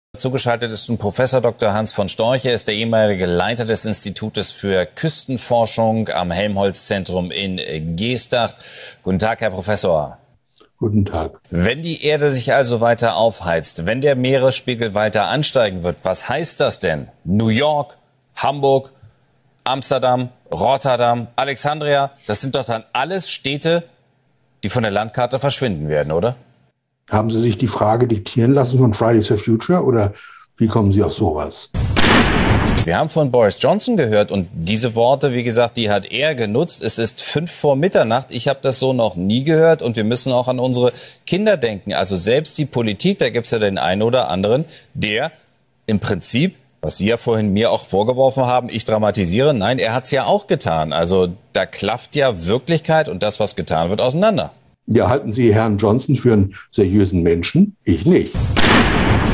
Prof. Dr. Hans von Storch, Klimaforscher und Meteorologe, im Interview mit der WELT.